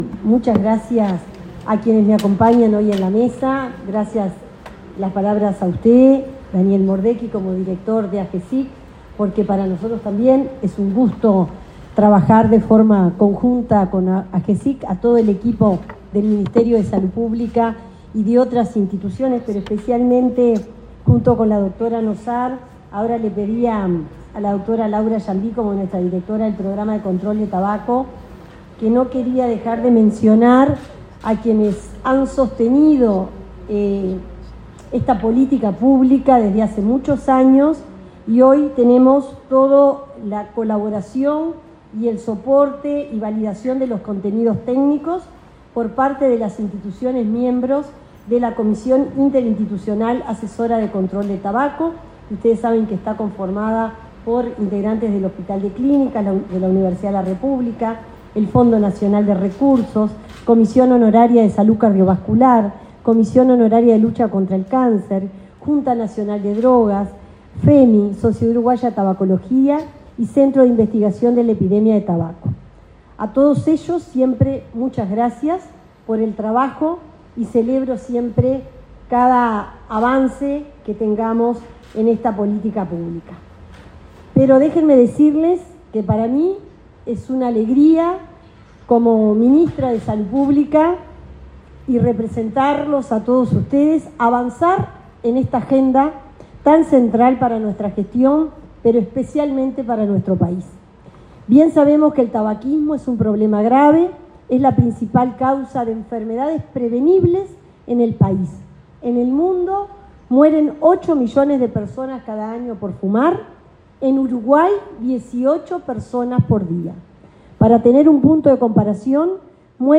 Palabras de la ministra de Salud Pública, Cristina Lustemberg 14/11/2025 Compartir Facebook X Copiar enlace WhatsApp LinkedIn La ministra de Salud Pública, Cristina Lustemberg, se expresó durante el lanzamiento del primer asistente virtual para dejar de fumar, acto realizado en la sede de la secretaría de Estado.